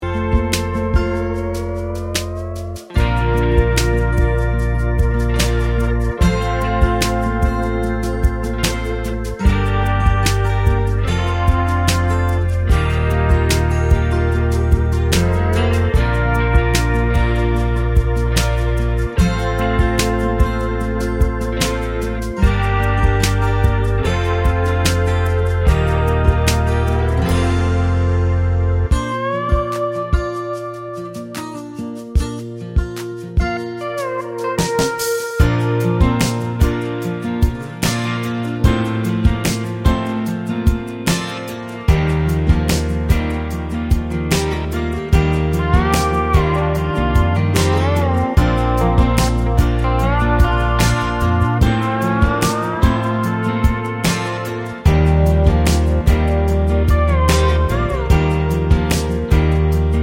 no Backing Vocals Country